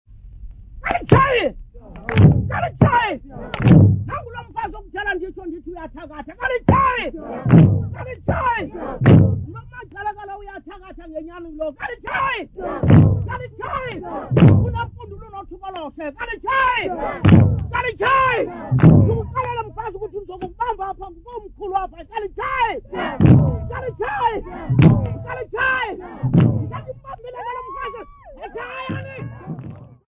Amampondo locals
Folk music
Sacred music
Field recordings
Africa South Africa Langa, Cape Town sa
Traditional Xhosa song with chanting and brief talking accompanied by drumming